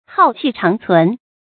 浩气长存 hào qì cháng cún
浩气长存发音